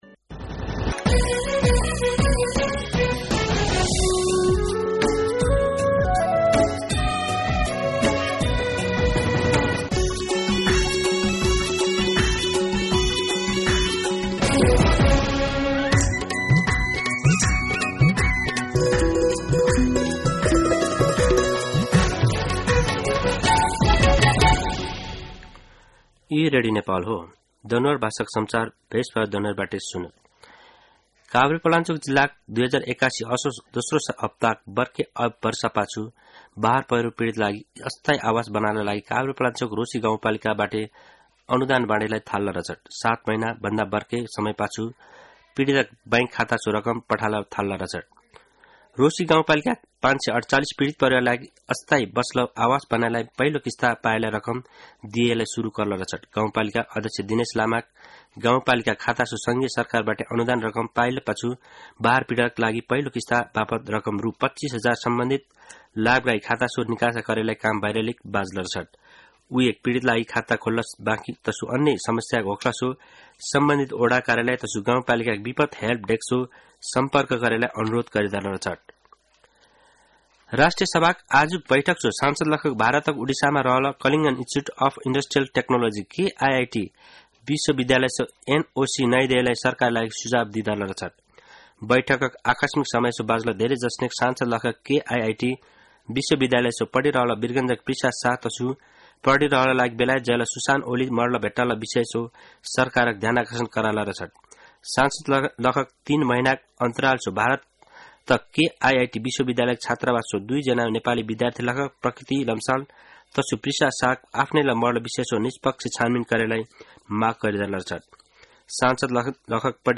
दनुवार भाषामा समाचार : २१ वैशाख , २०८२
Danuwar-News.mp3